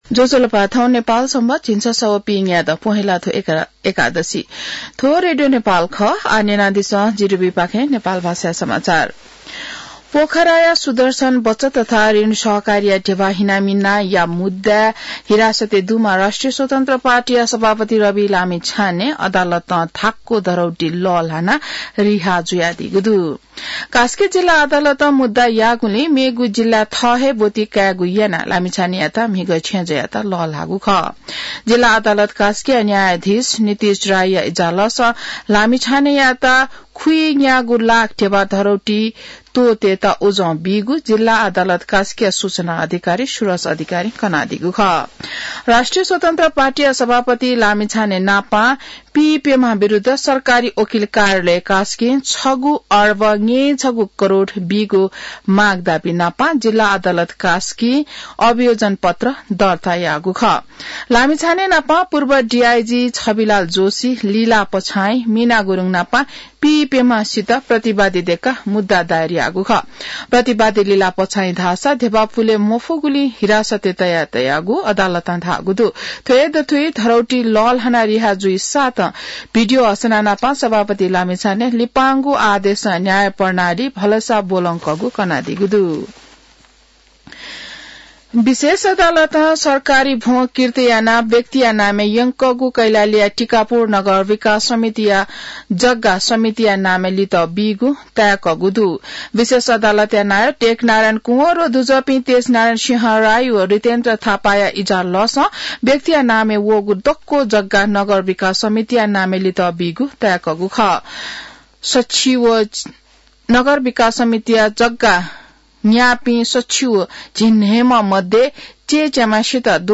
नेपाल भाषामा समाचार : २७ पुष , २०८१